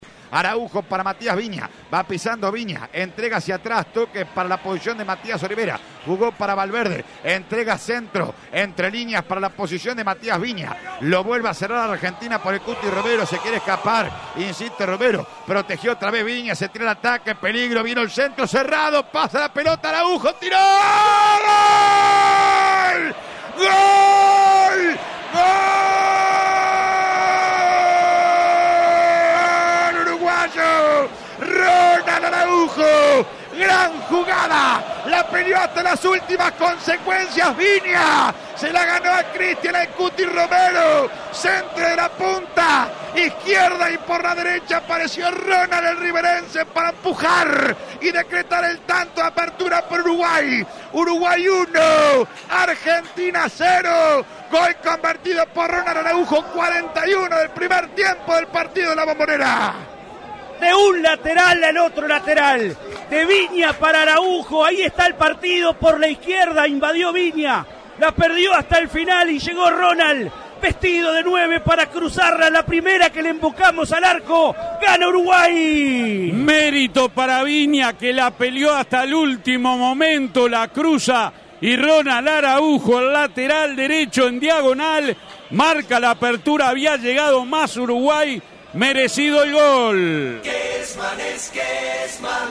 ESCUCHÁ LOS GOLES EN EL RELATO